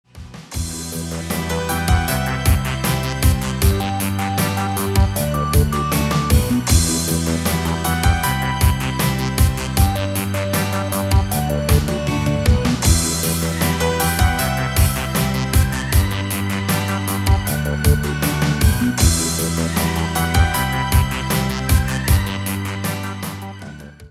• Качество: 320, Stereo
громкие
мелодичные
инструментальные
indie rock
русский рок
лиричные